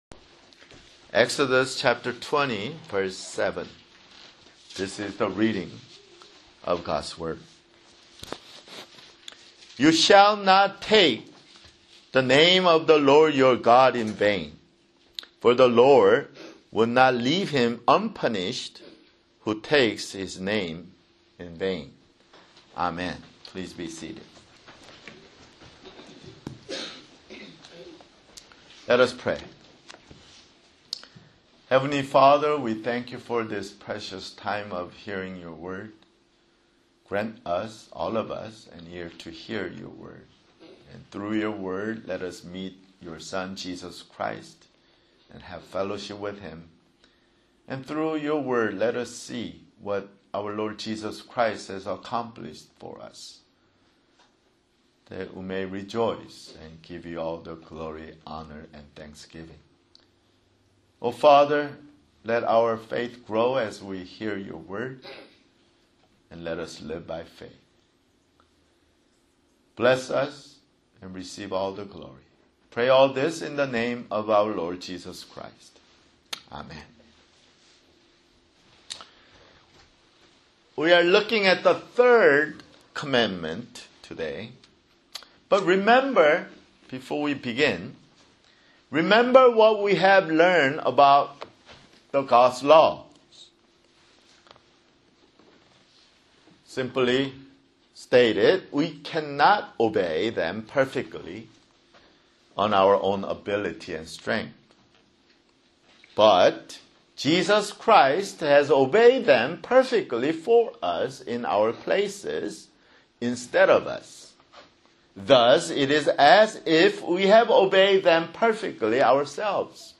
[Sermon] Exodus (50)